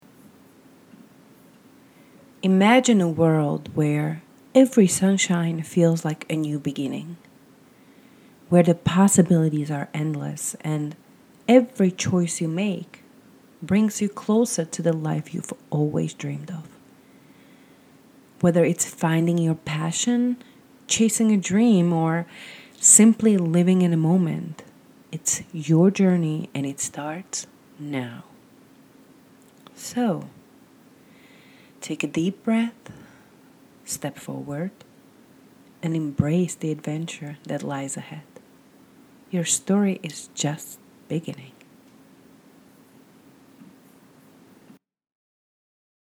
Voiceover Reel.mp3